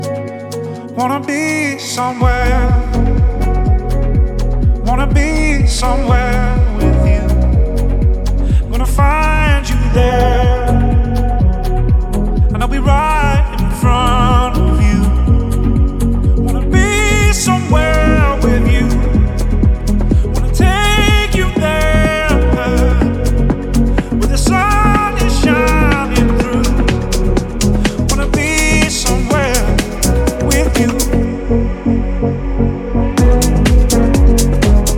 Модульные синтезаторы и глитчи трека
Жанр: Электроника